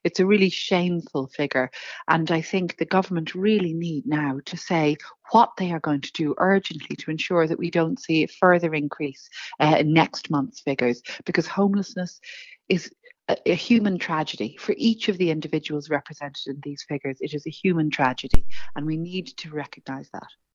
Ivana Bacik says the government needs to spell out what it intends to do to rectify the problem: